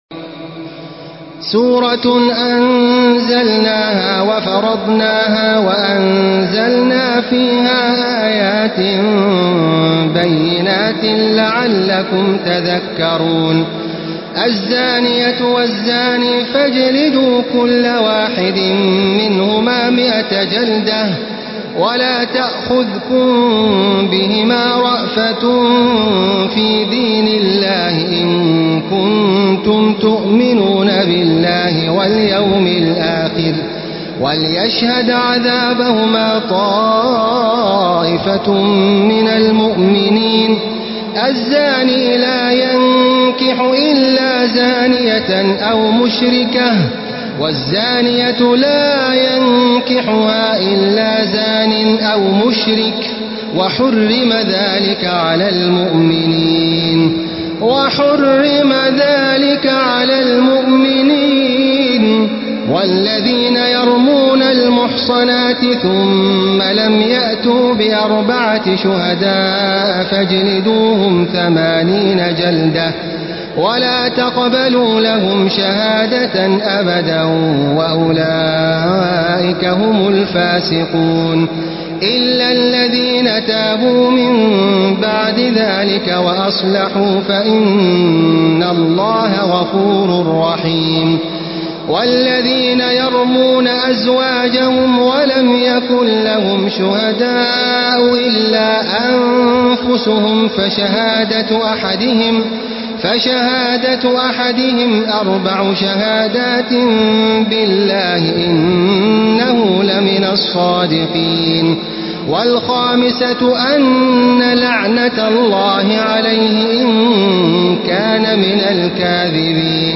تراويح الحرم المكي 1435
مرتل